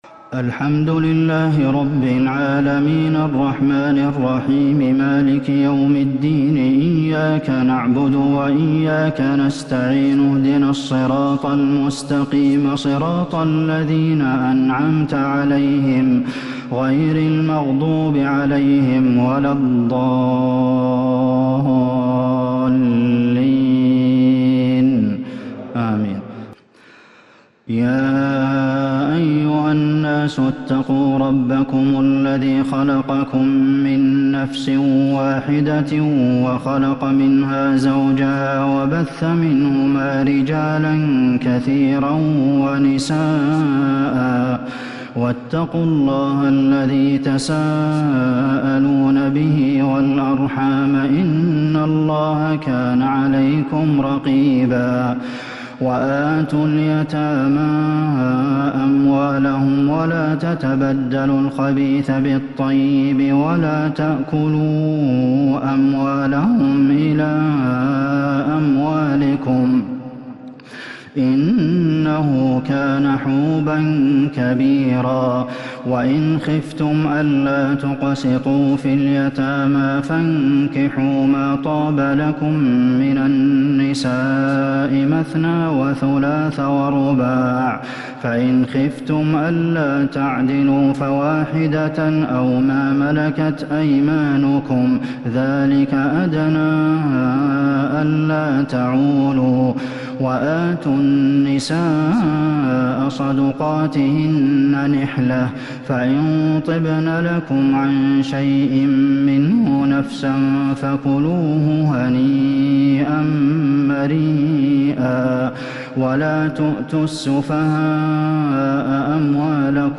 تراويح ليلة 6 رمضان 1442 من سورة النساء (1-42) Taraweeh 6st night Ramadan 1442H > تراويح الحرم النبوي عام 1442 🕌 > التراويح - تلاوات الحرمين